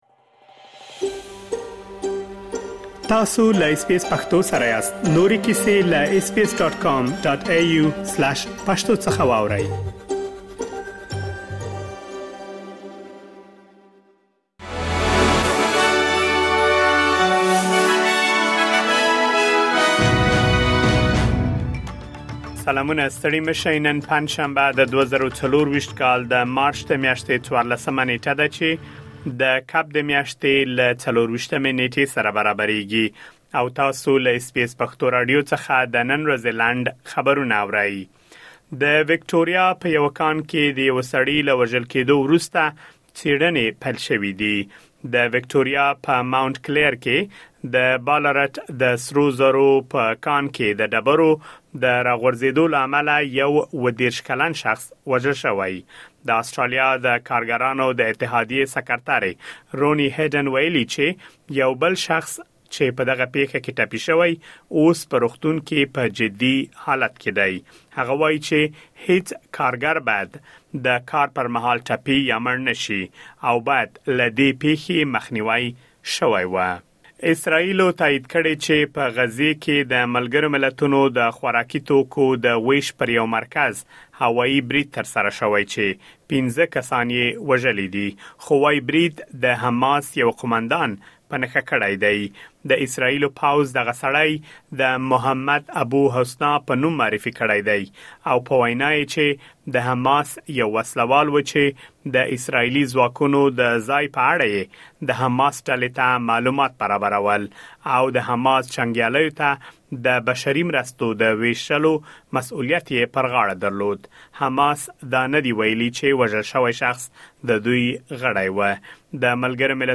د اس بي اس پښتو راډیو د نن ورځې لنډ خبرونه دلته واورئ.